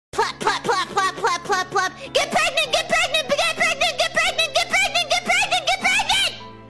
Plap Sound Effect Free Download
Plap